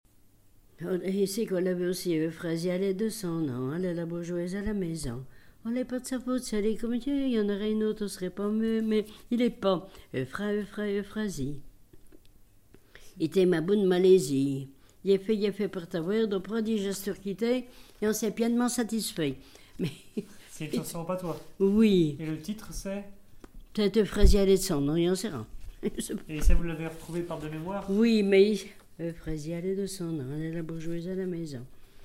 chanson en patois
Saint-Valérien
Pièce musicale inédite